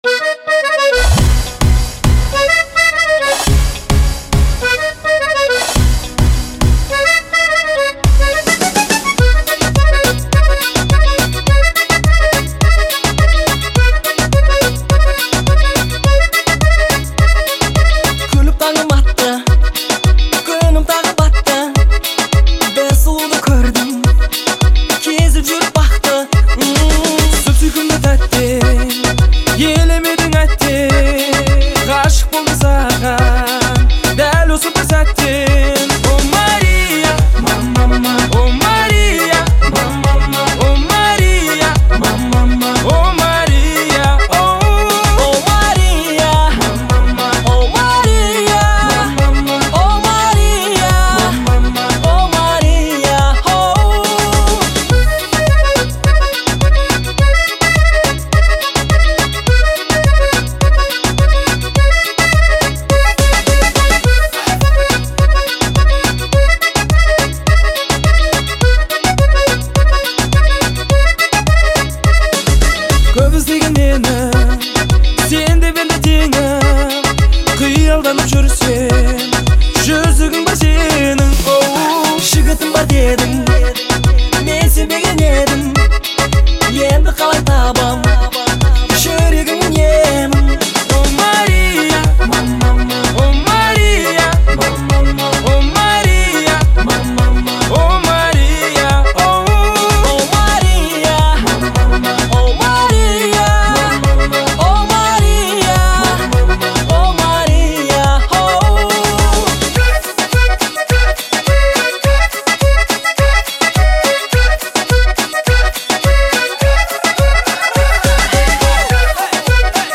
это душевная песня в жанре поп